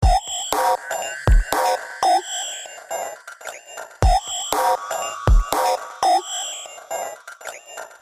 描述：Another frog chirping.
标签： frog night
声道立体声